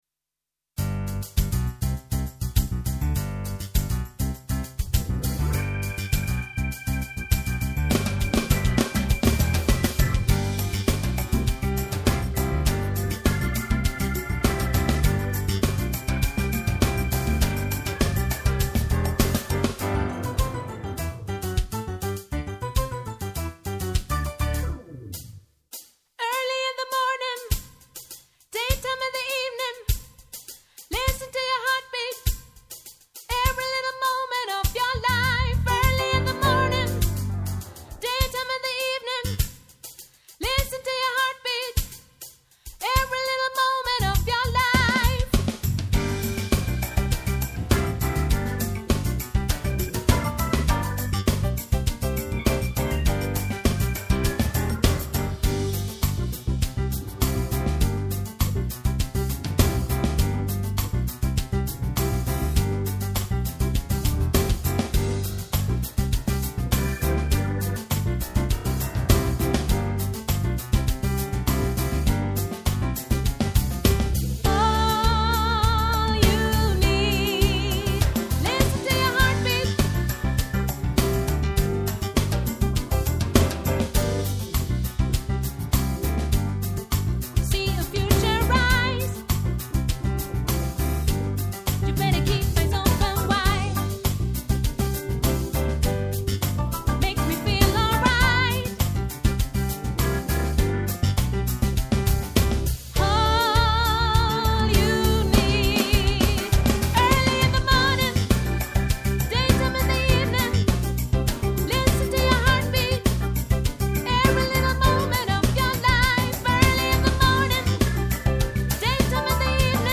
Listen_to_your_heartbeat_Sopr.mp3